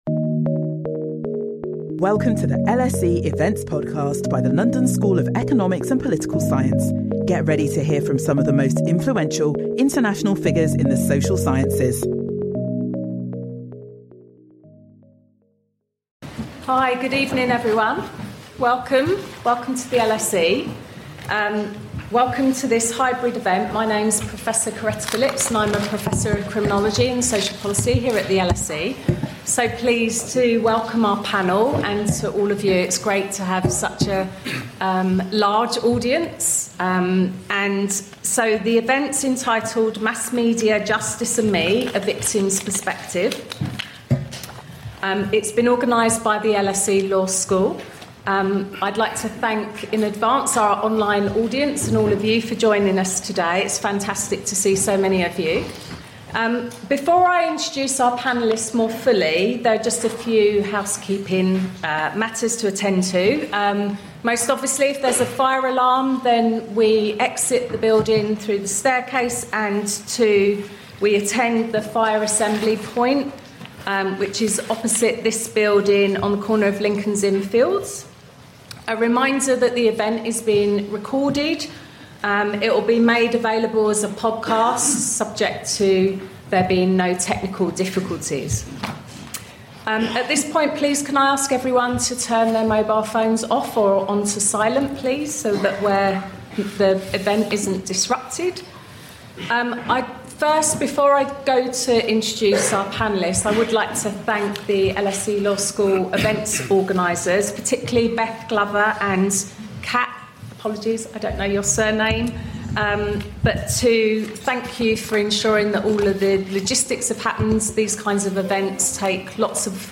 This thought-provoking event brings together victims, legal experts, media voices and policymakers to explore how mass media can illuminate and distort the path to justice.
Through personal stories, panel debate and audience engagement, we’ll confront the good, the bad and the deeply complex issue of mass media – and its role in the pursuit of justice.